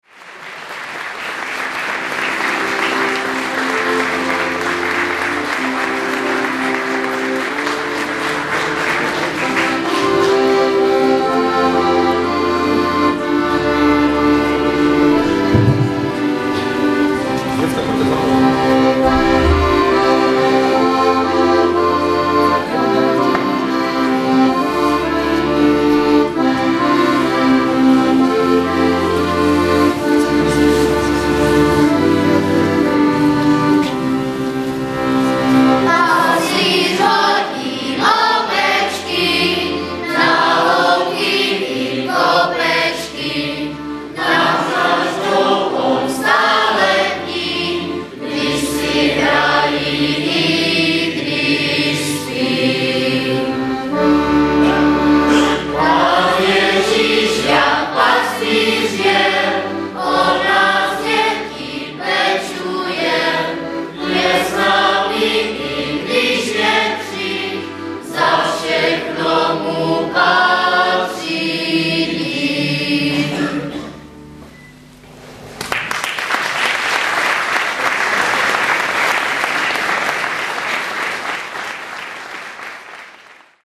● zvukový záznam bystřického vánočního koncertu ●